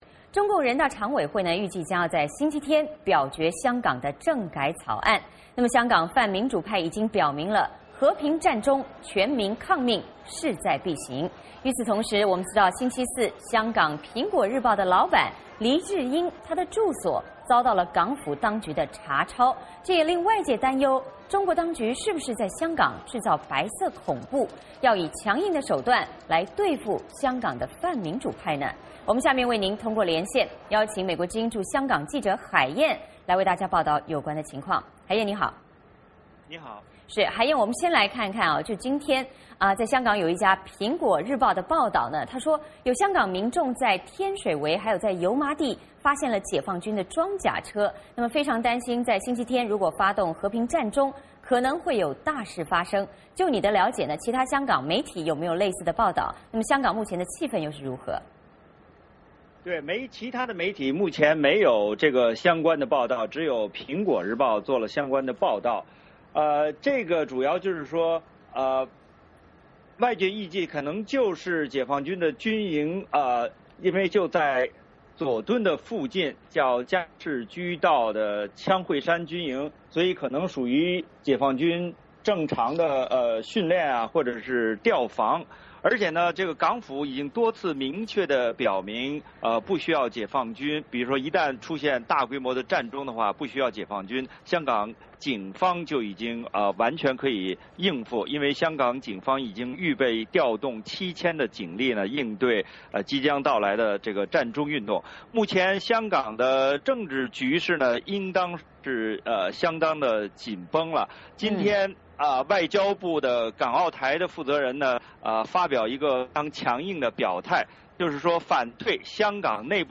VOA连线：政改方案将表决，香港陷入白色恐怖